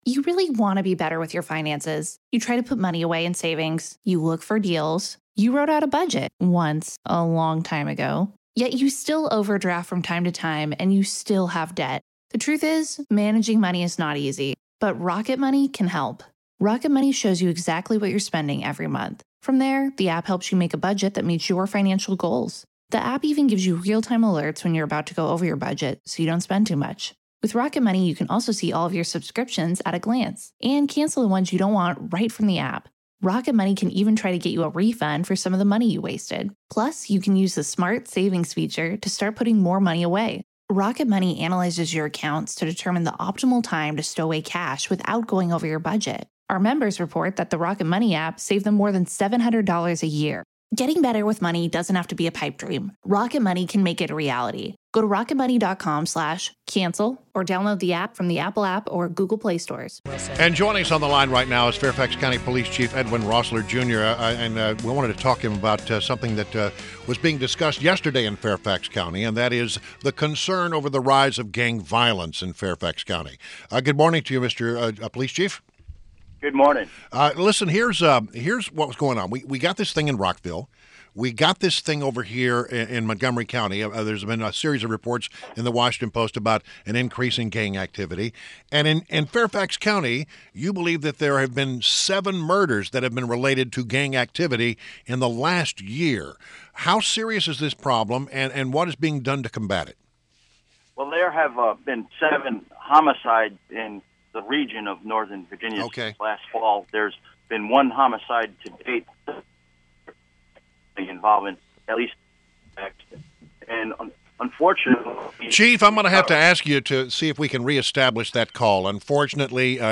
WMAL Interview - EDWIN ROESSLER - 03.22.17
INTERVIEW — Fairfax County Police Chief Col. Edwin Roessler Jr. – discussed the latest on the rise of Fairfax County gang activity.